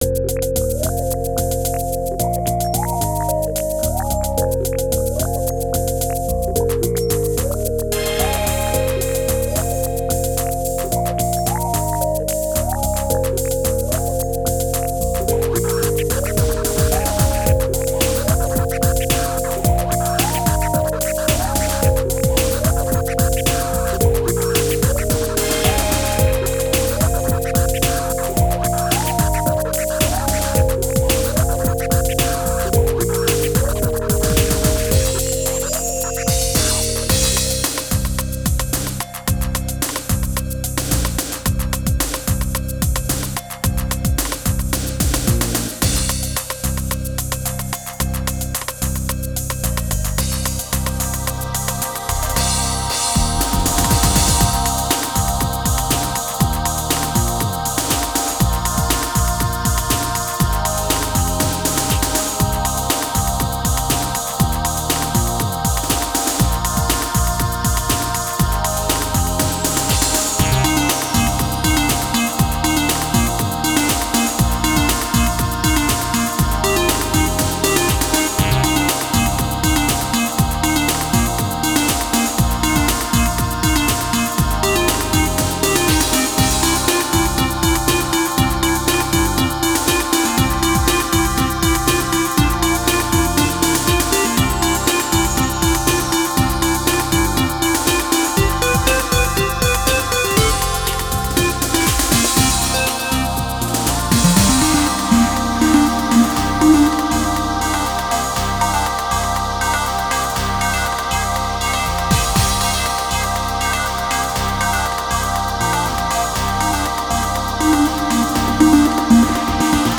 Style: Darkwave